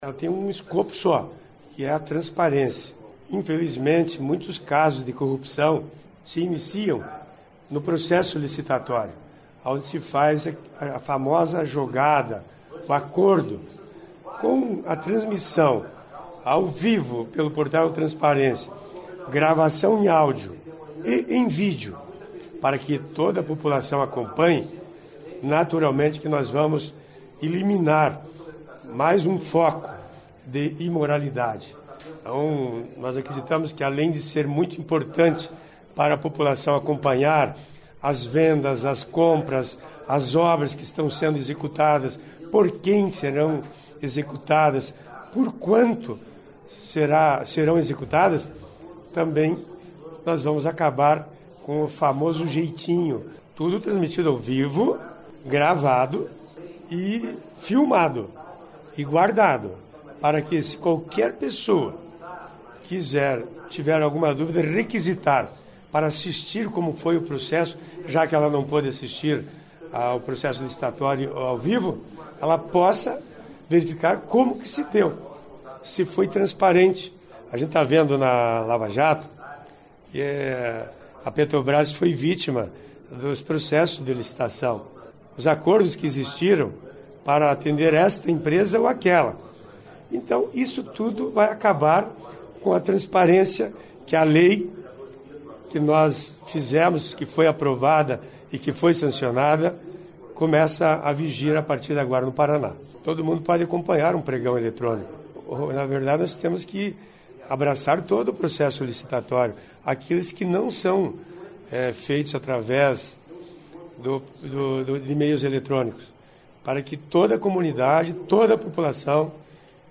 Ouça a entrevista com o autor da lei, o deputado Nereu Moura (MDB).